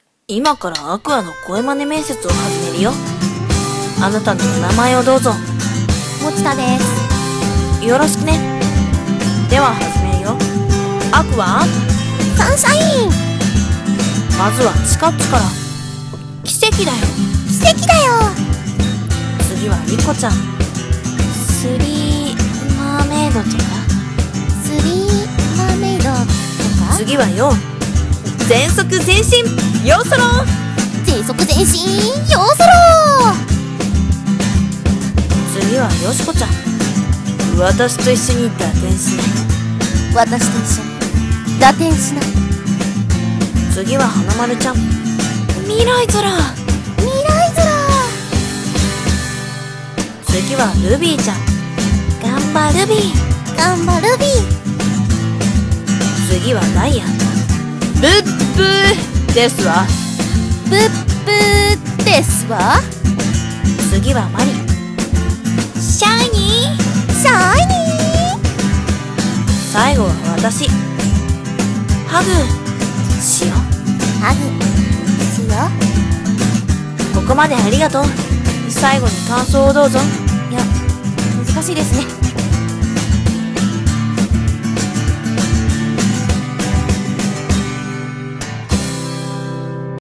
Aqours声真似面接